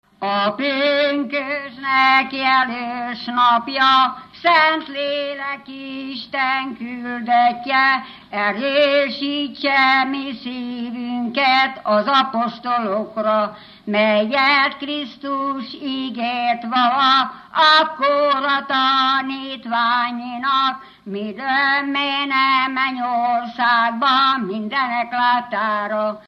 Alföld - Csongrád vm. - Tápé
Műfaj: Pünkösdölő
Stílus: 2. Ereszkedő dúr dallamok